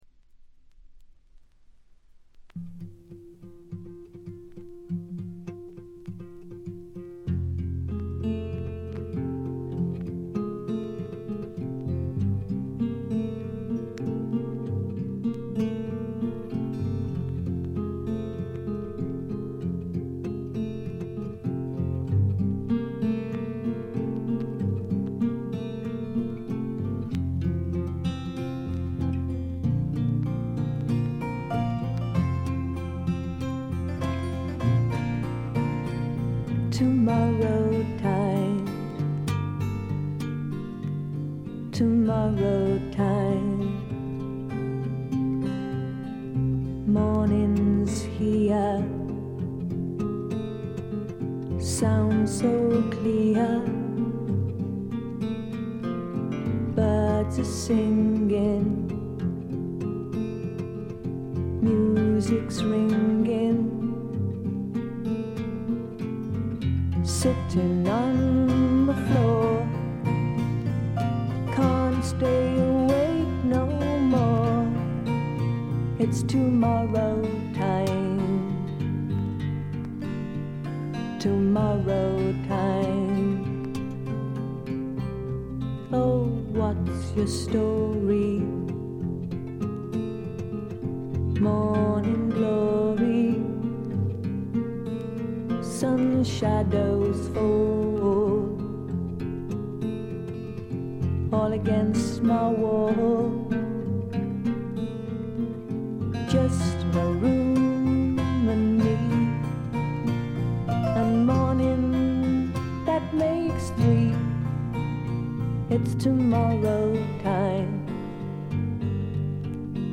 ごくわずかなノイズ感のみ。
音の方はウッドストック・サウンドに英国的な香りが漂ってくるという、この筋の方にはたまらないものに仕上がっています。
試聴曲は現品からの取り込み音源です。